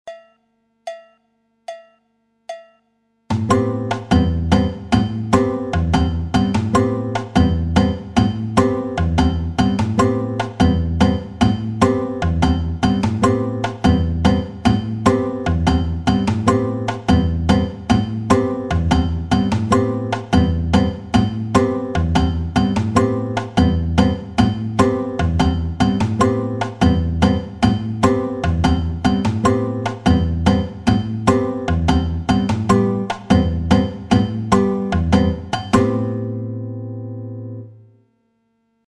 Figure 1 du partido alto à la guitare.
Guitare seule, avec la section rythmique partido alto 1.